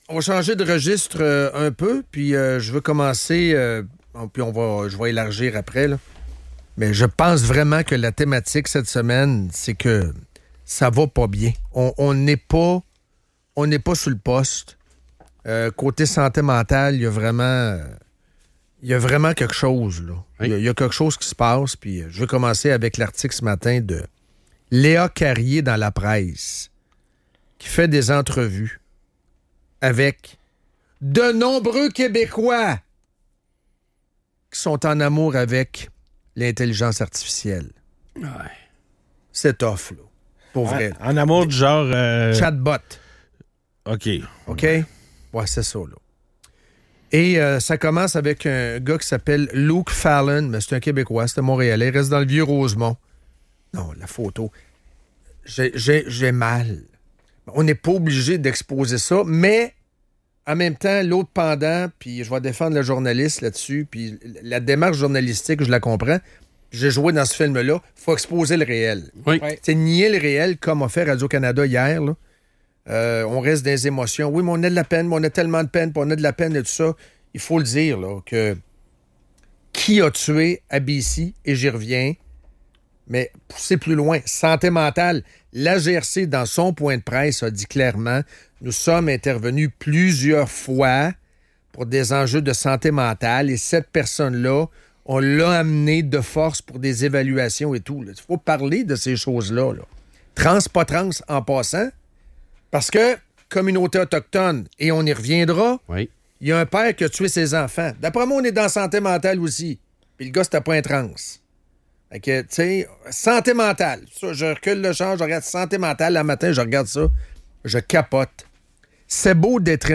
La santé mentale et la solitude sont au cœur des préoccupations, avec des exemples poignants de personnes isolées et en détresse. Les animateurs évoquent des tragédies récentes, notamment des tueries, soulignant le manque de soins psychologiques et l'impact des drogues sur les comportements.